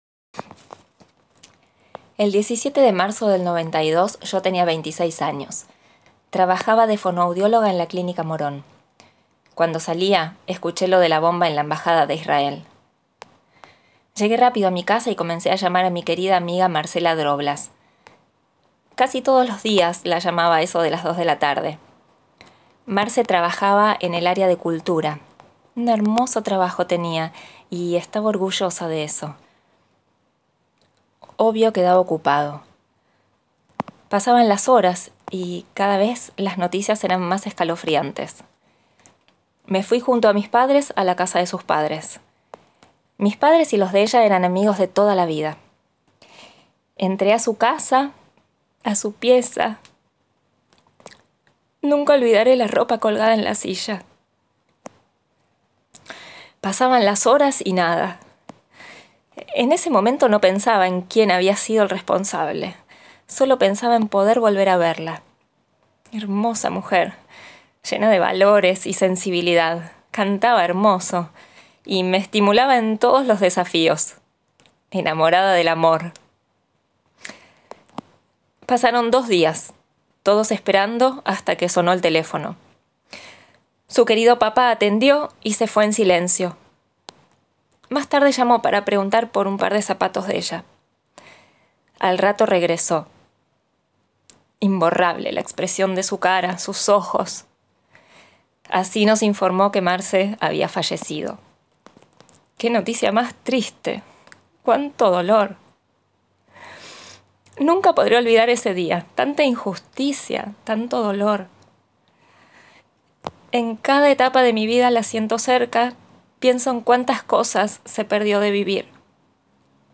3. Un testimonio
una interpretación del testimonio